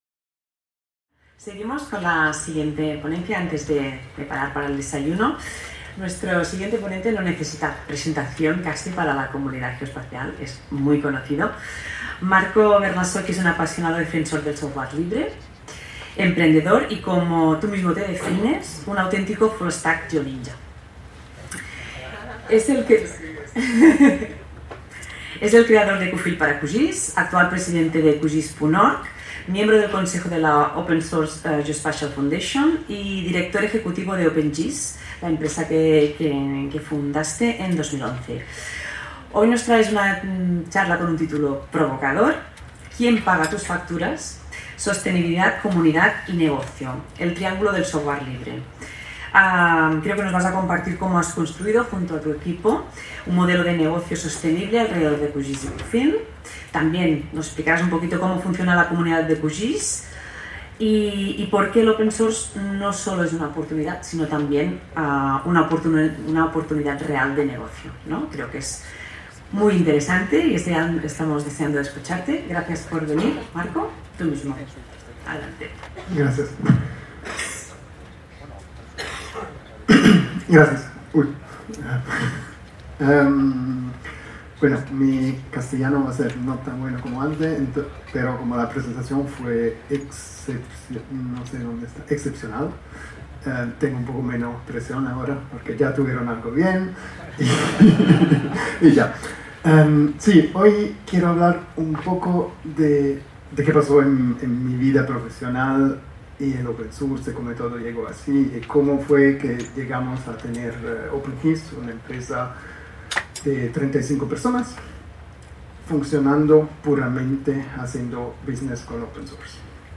en el marc de les 18enes Jornades de SIG Lliure 2025 organitzades pel SIGTE de la Universitat de Girona. Ponència sobre els programes QGIS i QField i com funciona la comunitat open source i com pot contribuir a la sostenibilitat i a treballar en els objectius de desenvolupament sostenible  Aquest document està subjecte a una llicència Creative Commons: Reconeixement – No comercial – Compartir igual (by-nc-sa) Mostra el registre complet de l'element